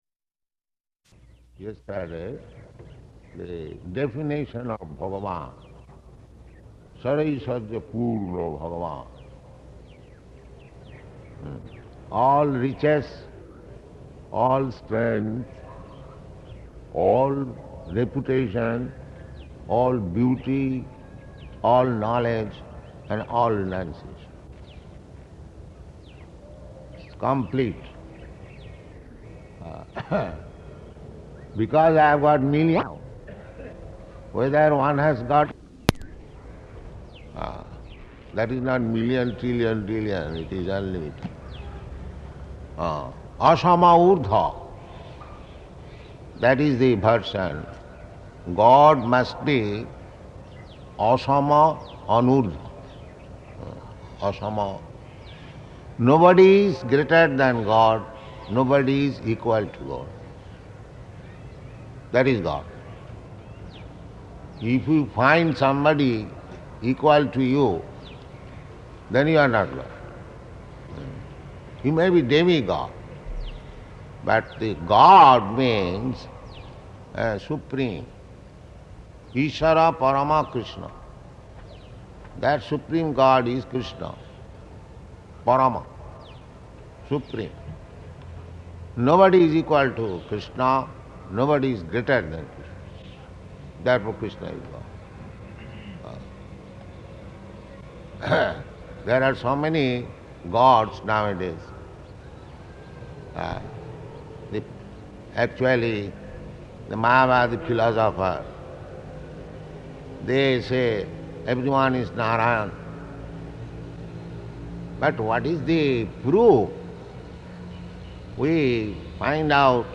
Lecture
Lecture --:-- --:-- Type: Lectures and Addresses Dated: December 8th 1972 Location: Ahmedabad Audio file: 721208LE.AHM.mp3 Prabhupāda: ...yesterday the definition of Bhagavān, ṣaḍ-aiśvarya-pūrṇa-bhagavān [ Cc.